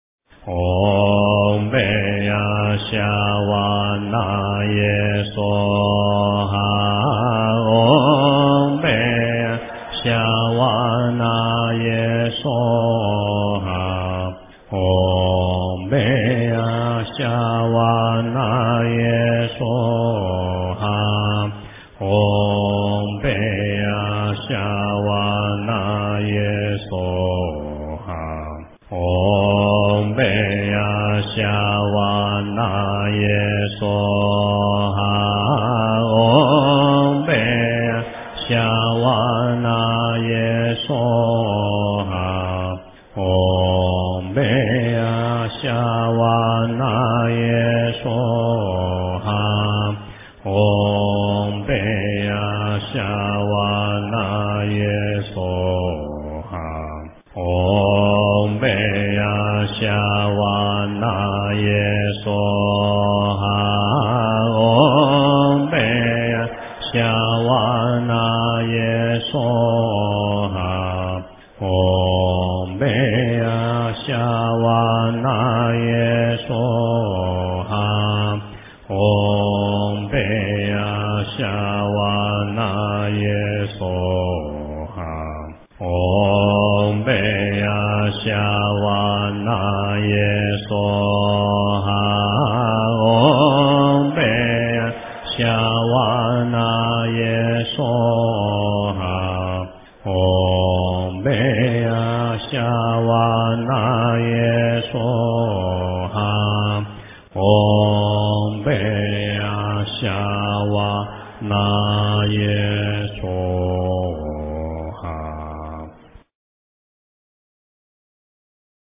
真言
佛音 真言 佛教音乐 返回列表 上一篇： 南无大愿地藏王菩萨-闽南语--净土Pure Land 下一篇： 佛顶尊胜陀罗尼中咒--李居明 相关文章 绿度母心咒--观自在密宗咒语 绿度母心咒--观自在密宗咒语...